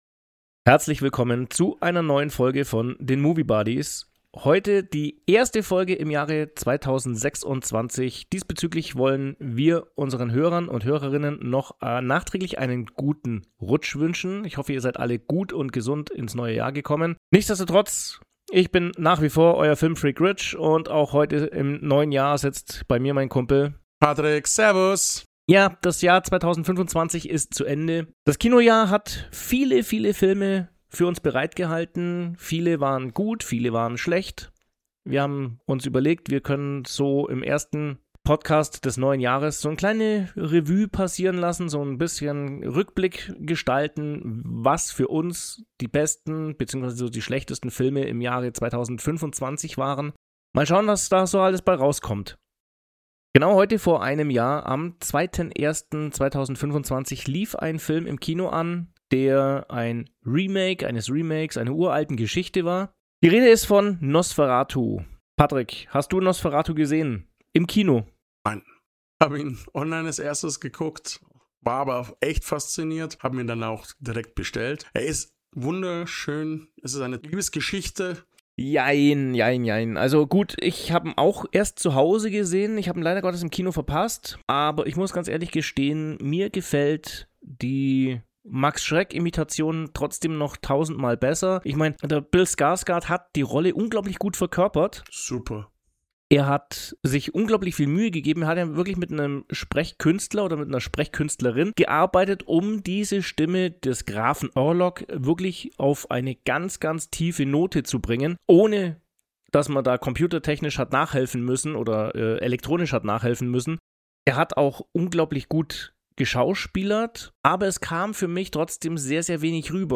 Nehmt Teil an einer unterhaltsamen Debatte über die Hochs und Tiefs im Kino 2025, die keine Wünsche mehr offen lassen dürfte.